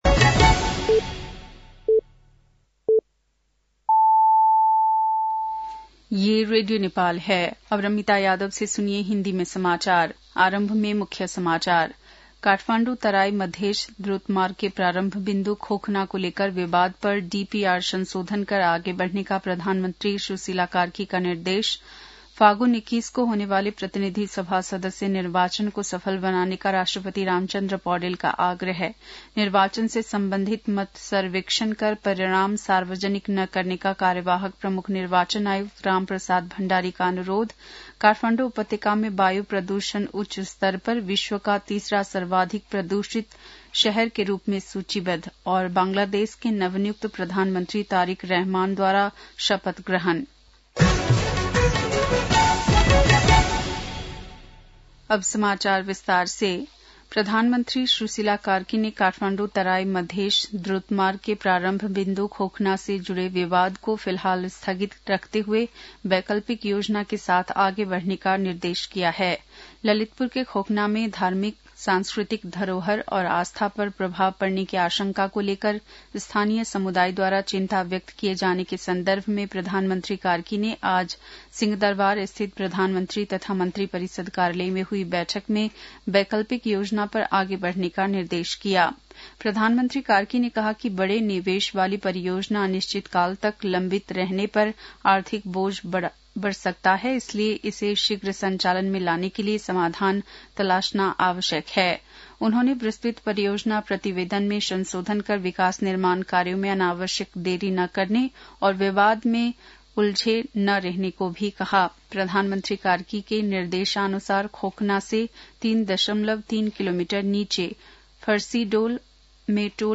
बेलुकी १० बजेको हिन्दी समाचार : ५ फागुन , २०८२